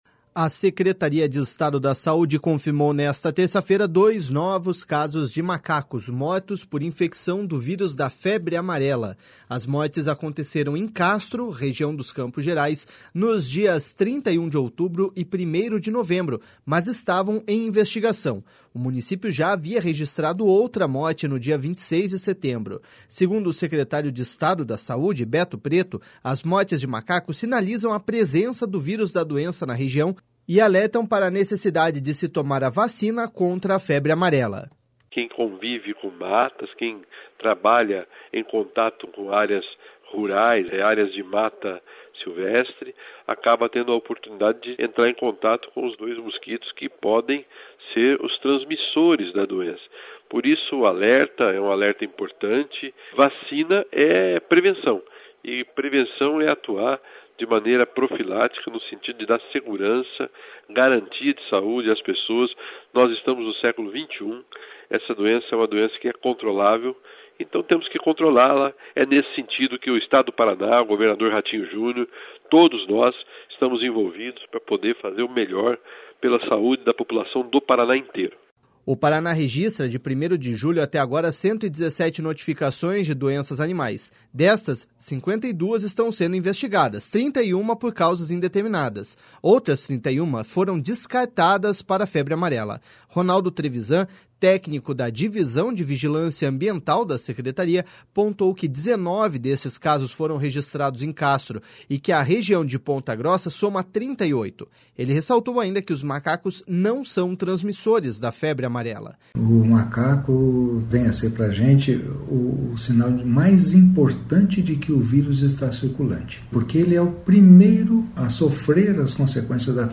Segundo o secretário de Estado da Saúde, Beto Preto, as mortes de macacos sinalizam a presença do vírus da doença na região e alertam para a necessidade de se tomar a vacina contra a doença.// SONORA BETO PRETO.//